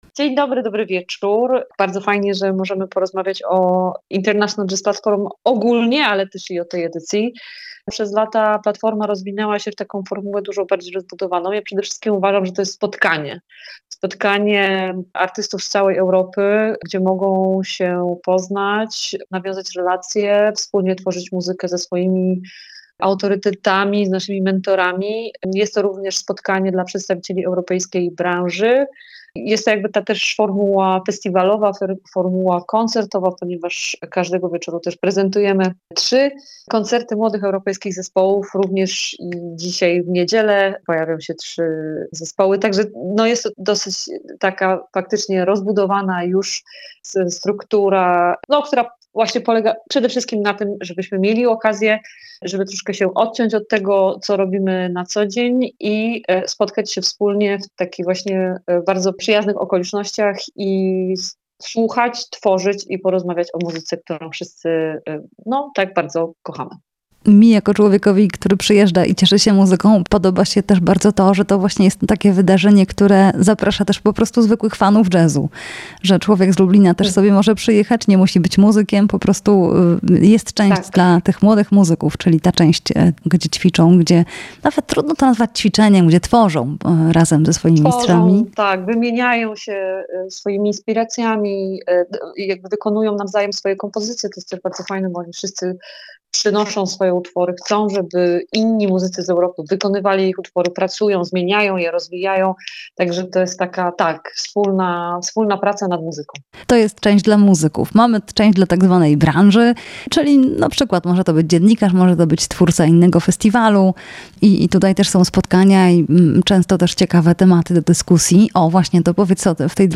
Jazz a vu: International Jazz Platfrorm [POSŁUCHAJ ROZMOWY]